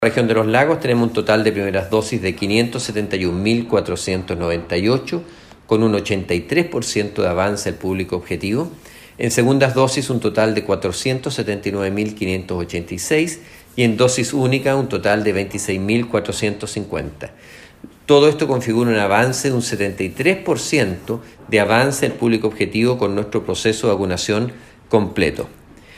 Así lo refrendó el seremi Alejandro Caroca, al detallar las cifras en cuanto al avance de vacunación.